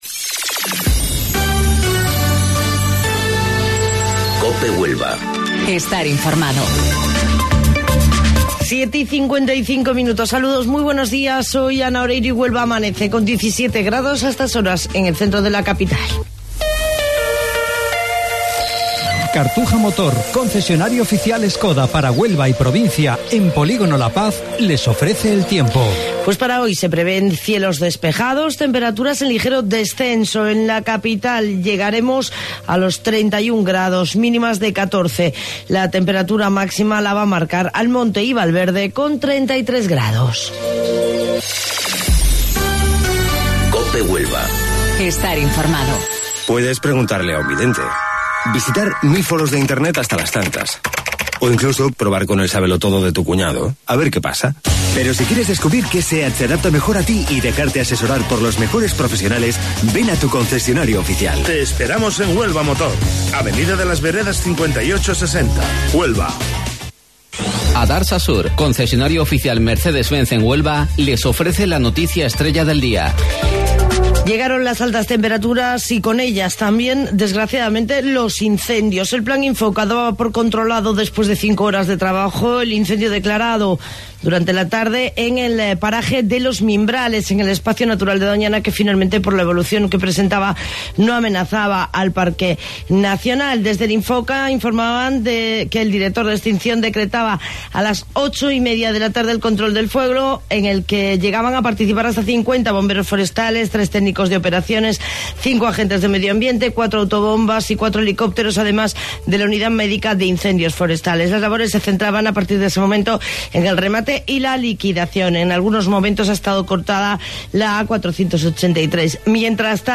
AUDIO: Informativo Local 08:25 del 14 de Mayo